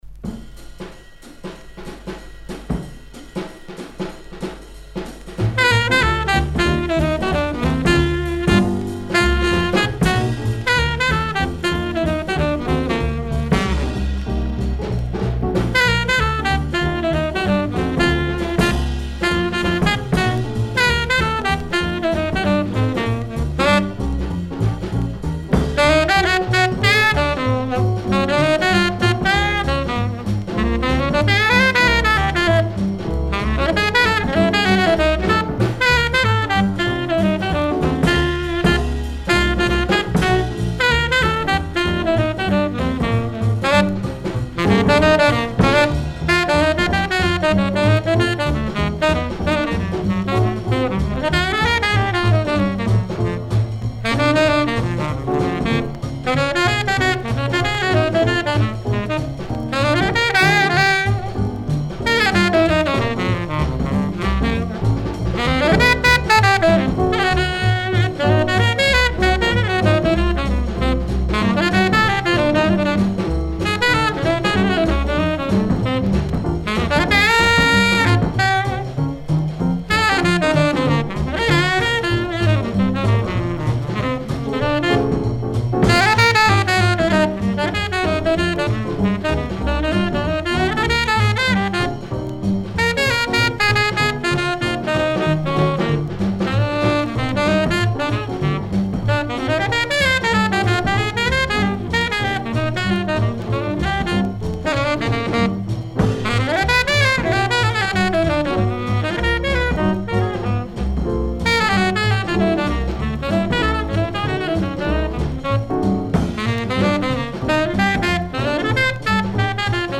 Recorded 1954 in Paris